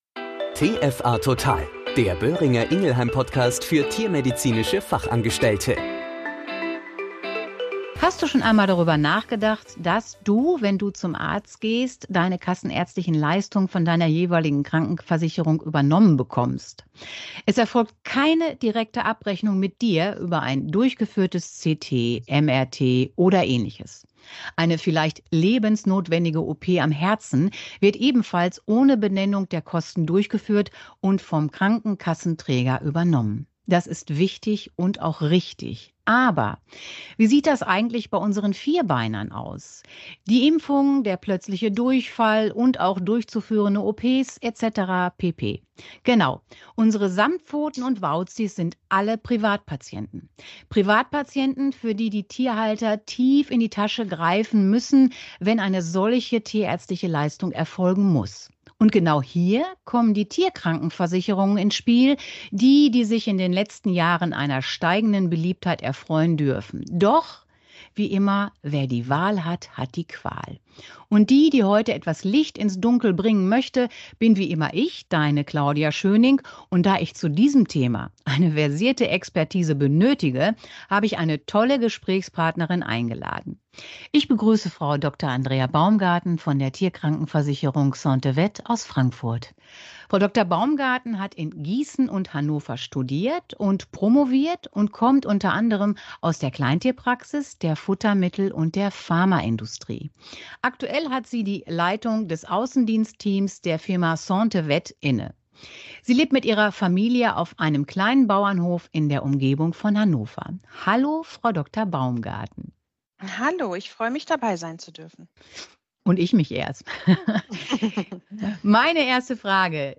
In diesem Interview mit einer versierten Kollegin aus diesem Bereich, sollen wertvolle Beratungsstipps und Impulse vermittelt werden, die gerade der TFA und auch dem Tierhalter einen Leitfaden im Beratungsgespräch sein sollen.